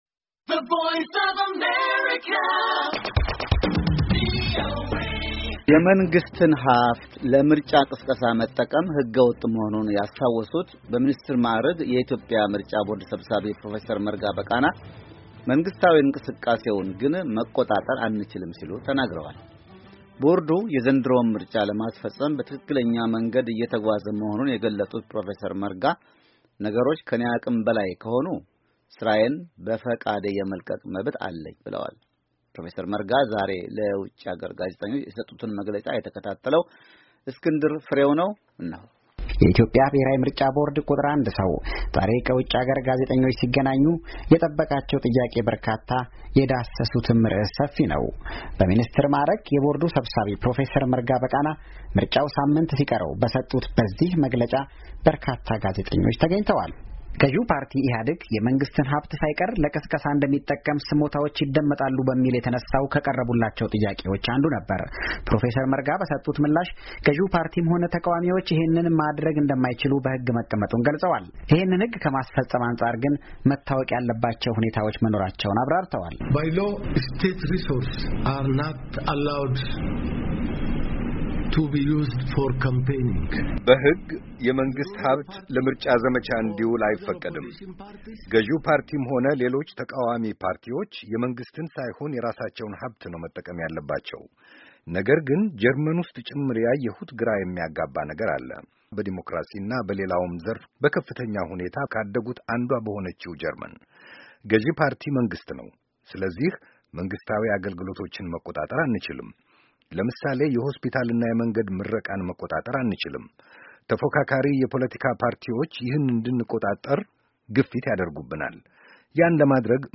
የመንግሥት እንቅስቃሴዎችን አንቆጣጠርም - ብሔራዊ ምርጫ ቦርድ ሰብሳቢ፣ የፕሮፌሰር መርጋ በቃና ጋዜጣዊ መግለጫ